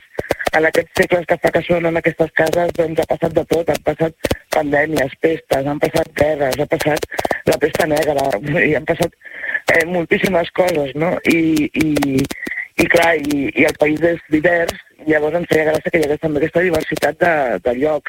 En una entrevista al Supermatí de Ràdio Capital